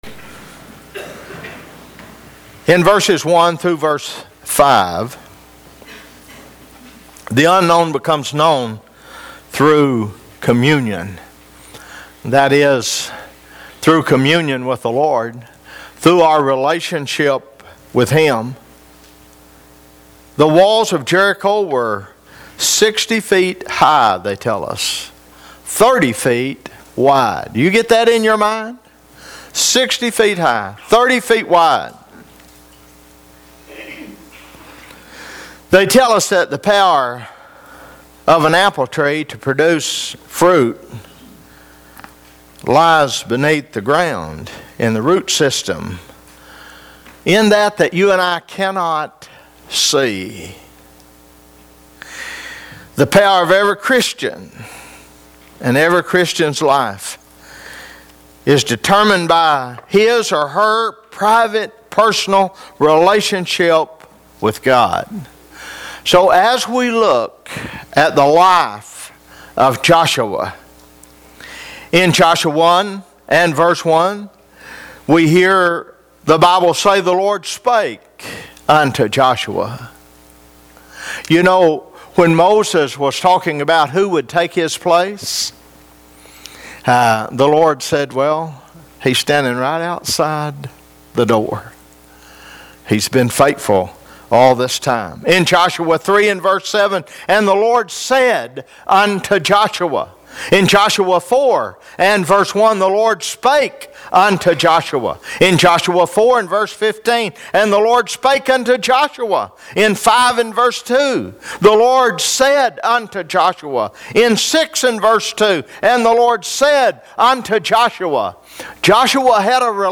Required fields are marked * Comment * Name * Email * Website ← Newer Sermon Older Sermon →